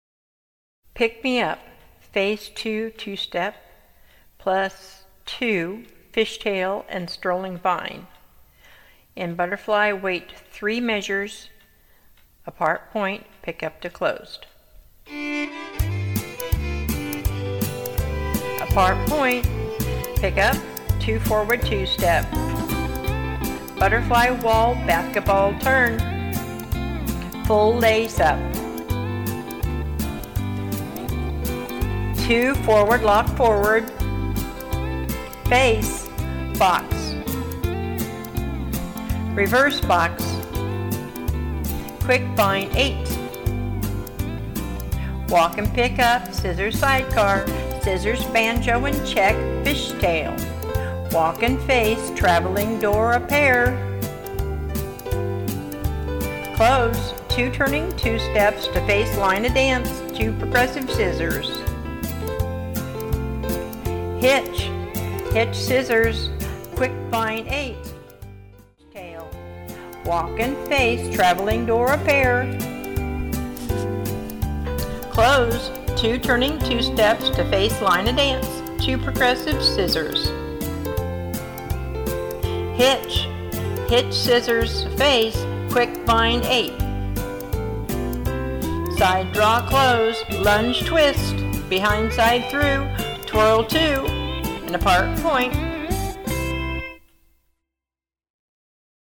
Round Dance
Two Step